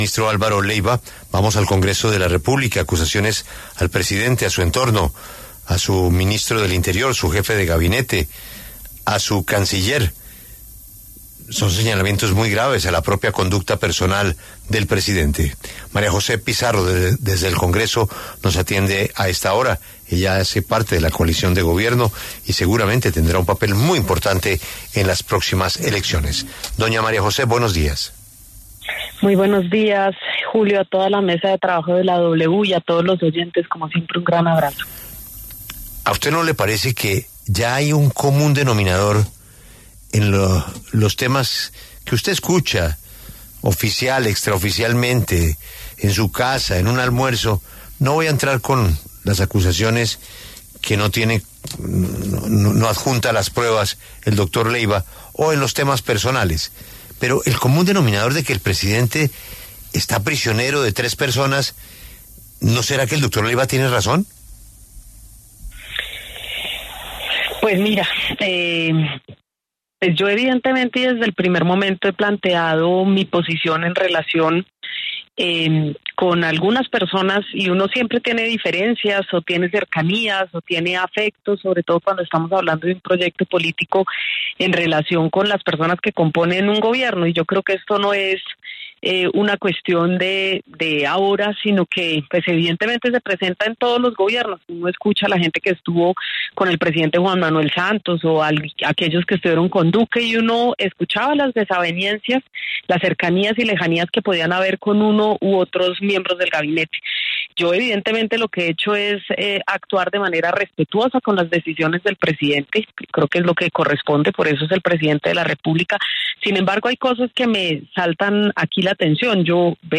Desde el Pacto Histórico, la senadora María José Pizarro, pasó por los micrófonos de La W.